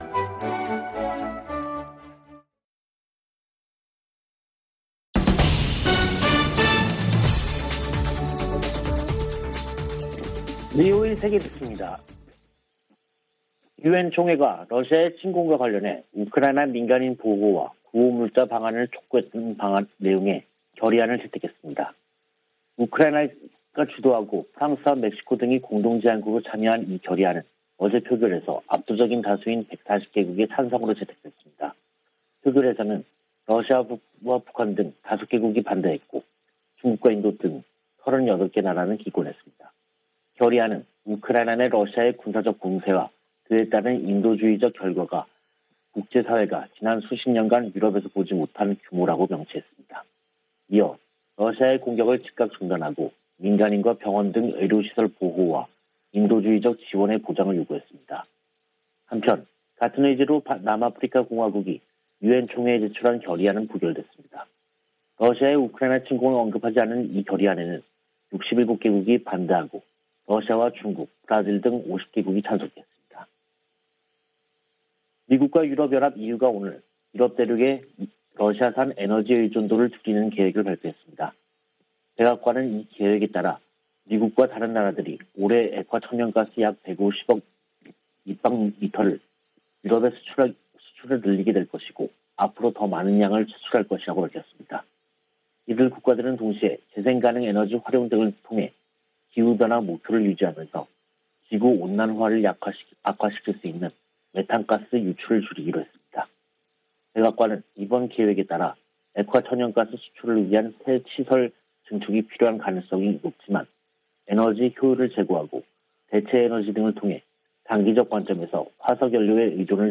VOA 한국어 간판 뉴스 프로그램 '뉴스 투데이', 2022년 3월 25일 3부 방송입니다. 북한은 24일 발사한 탄도미사일이 신형 ICBM인 '화성-17형'이라며 시험발사에 성공했다고 밝혔습니다. 미국 정부는 북한이 ICBM으로 추정되는 장거리 탄도미사일을 발사한 데 강력한 규탄 입장을 밝혔습니다. 미국 정부가 북한 미사일 프로그램에 연관된 북한과 러시아의 개인과 회사들을 제재했습니다.